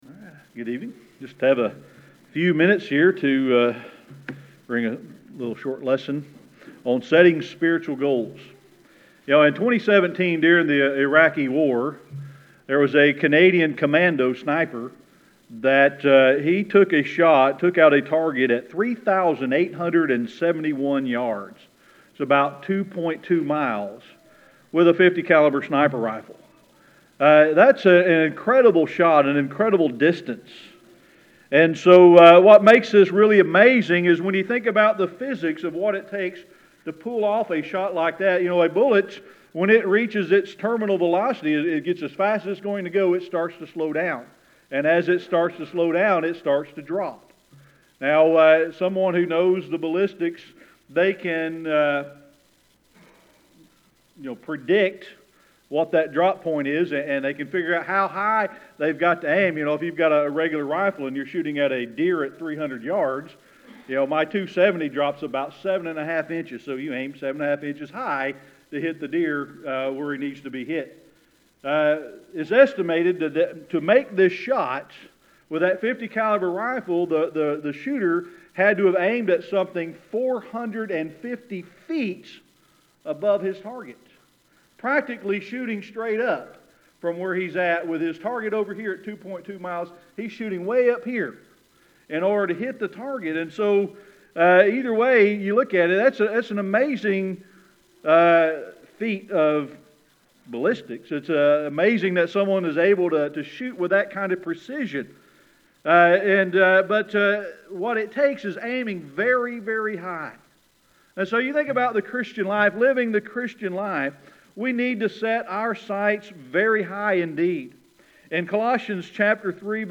Series: Sermon Archives
Philippians 3:13-14 Service Type: Sunday Evening Worship I have just have a few minutes here to bring a little short lesson on setting spiritual goals.